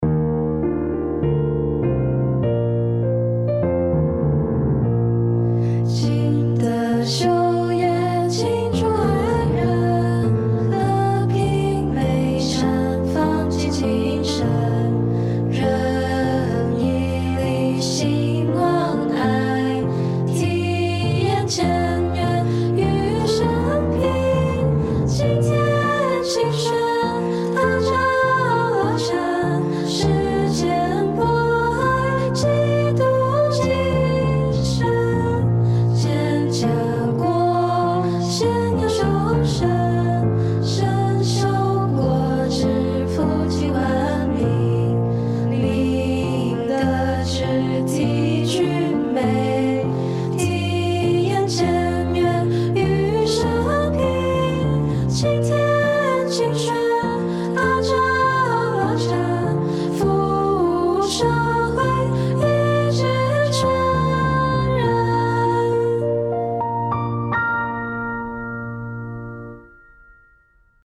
校歌SFAC School Anthem 100bpm.mp3